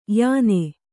♪ hāne